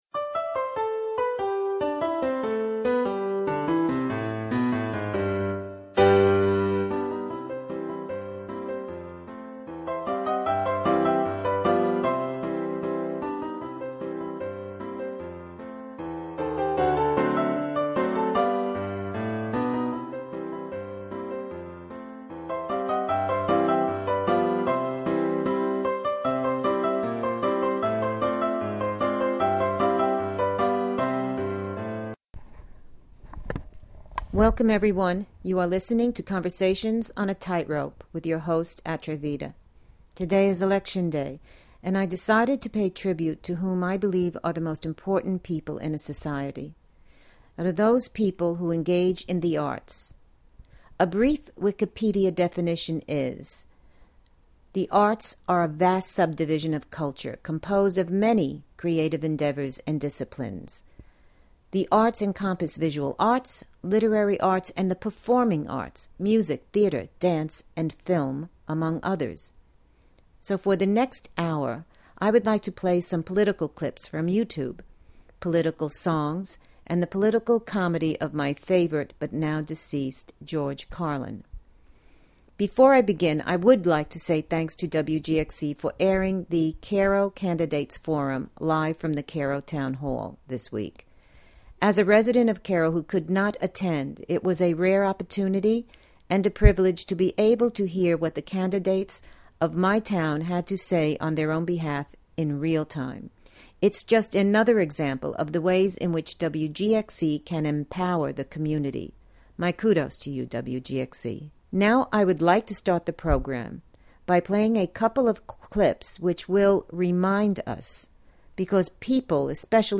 Political commentary, songs, and George Carlin comedy audios Play In New Tab (audio/mpeg) Download (audio/mpeg)